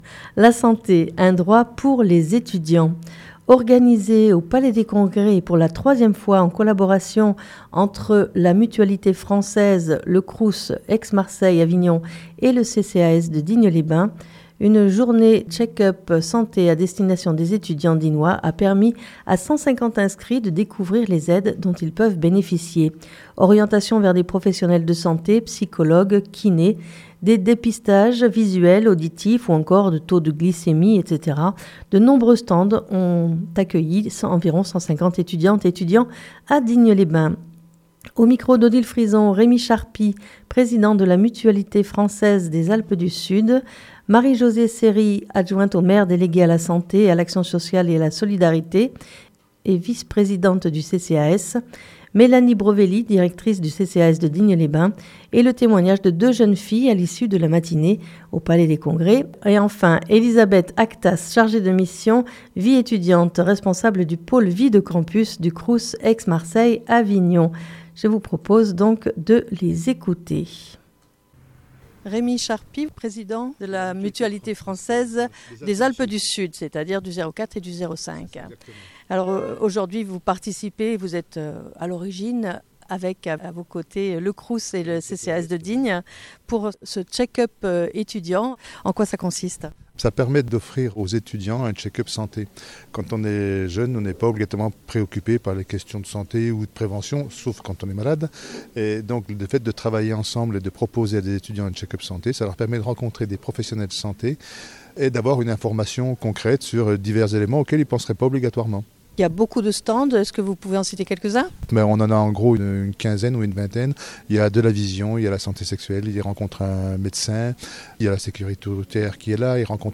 puis le témoignage de 2 jeunes filles à l'issue de la matinée au Palais des Congrès